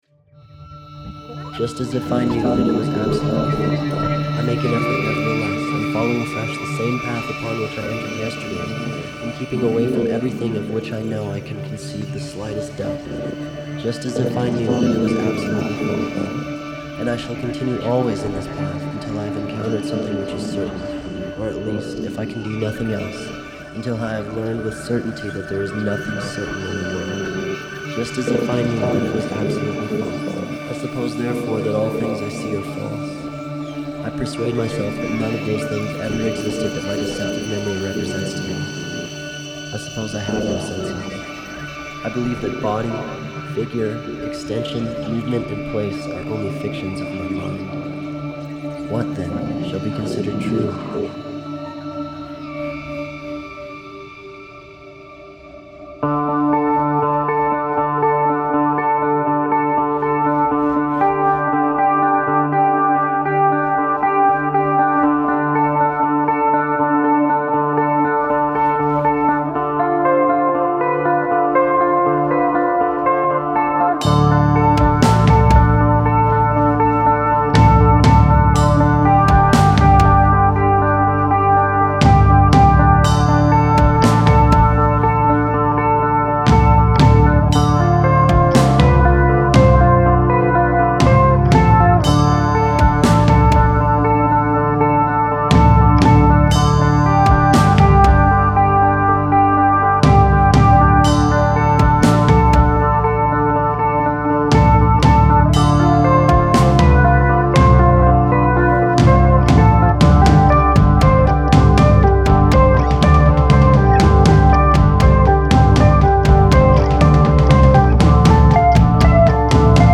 soft melancholic touch
phaser-powered guitar lines